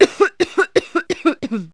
/ cdmania.iso / sounds / illsnds / cough3.wav ( .mp3 ) < prev next > Waveform Audio File Format | 1996-04-15 | 19KB | 1 channel | 22,050 sample rate | 1 second
cough3.mp3